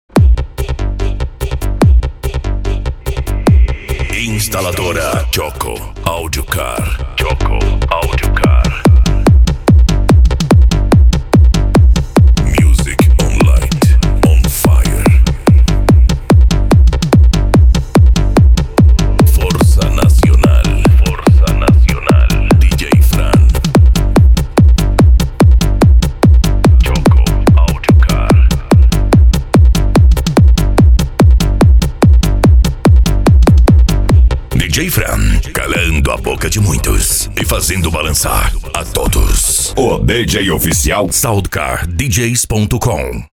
Bass
Racha De Som
Remix